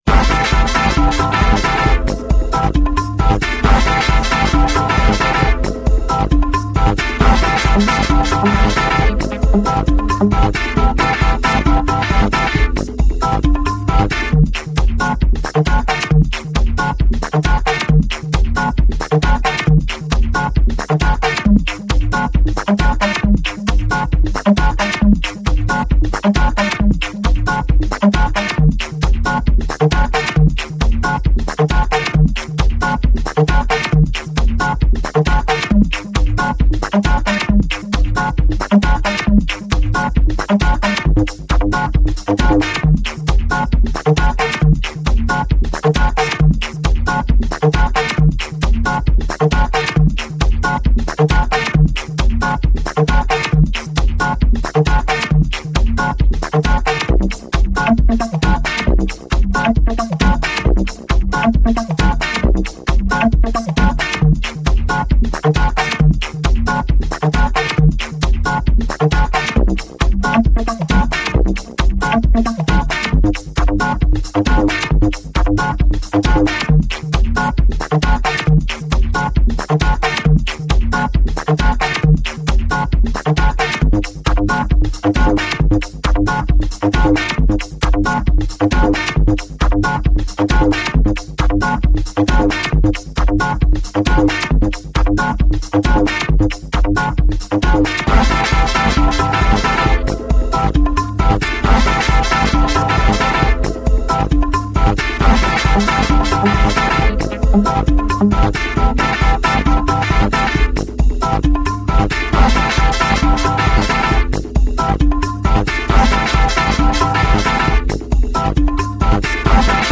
2) bigger file, better sound, stereo, 40kb/sec :